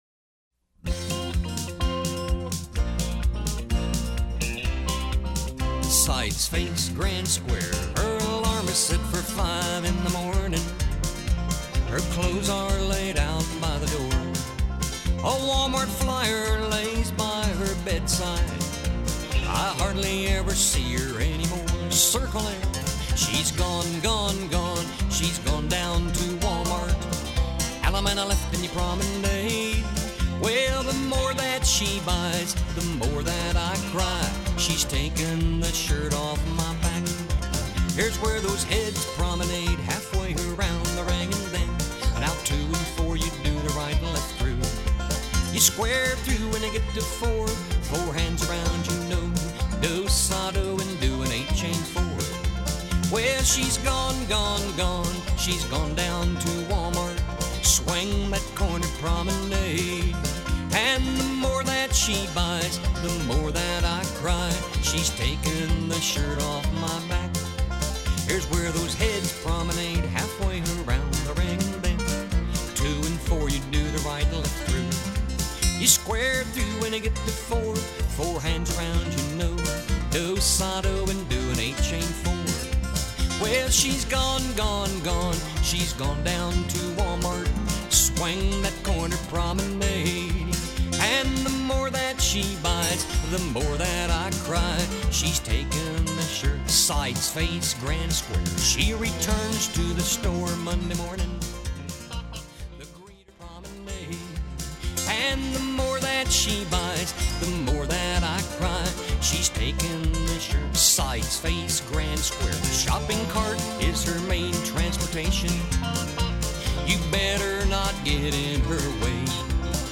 SC = Singing Call